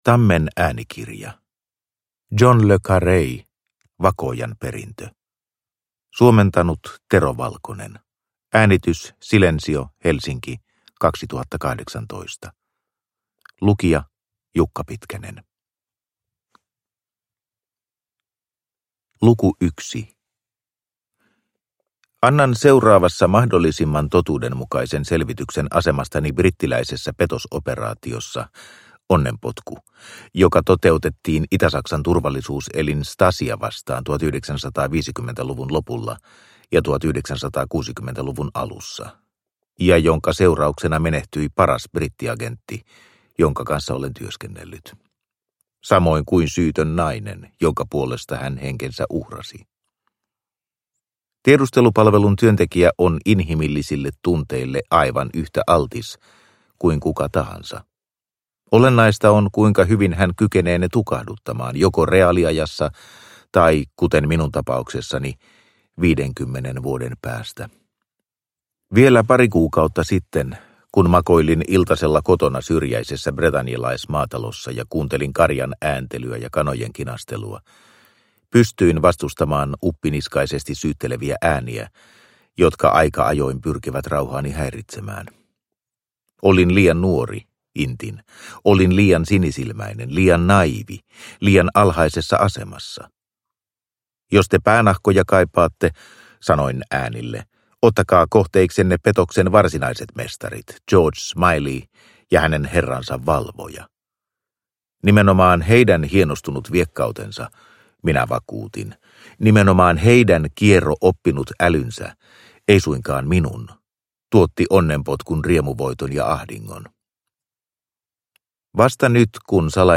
Vakoojan perintö – Ljudbok – Laddas ner